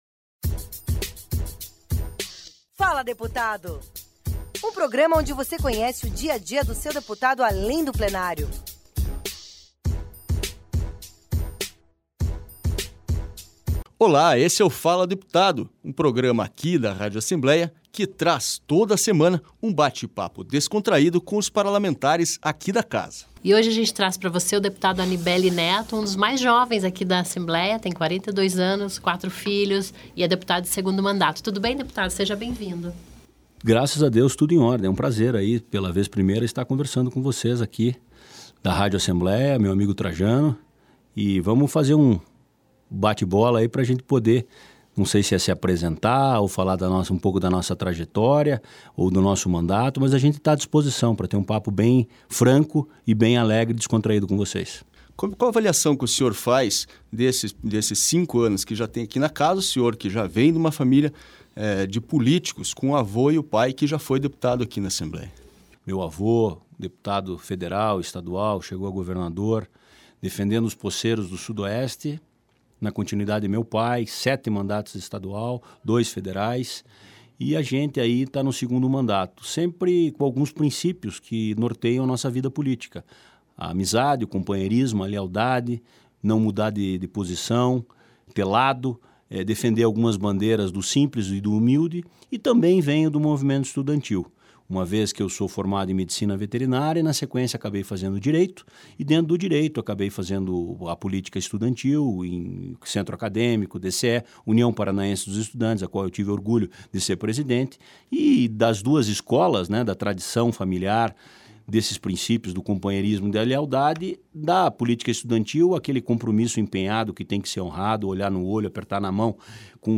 Dá o play e ouça a íntegra da entrevista com o deputado Anibelli Neto (PMDB).